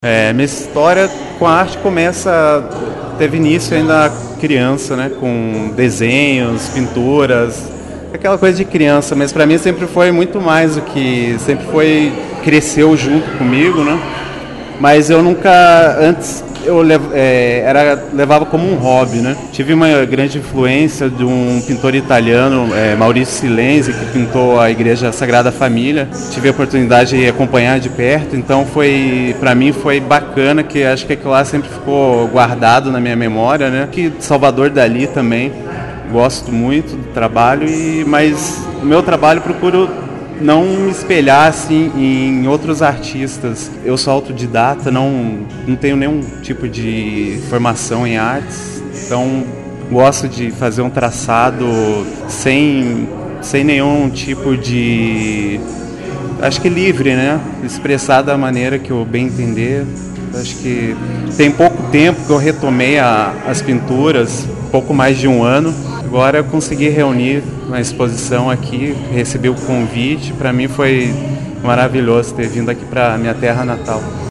conversou com o jornalismo da Rádio Colmeia, e destacou o seu trabalho e quem são os seus artistas inspiradores.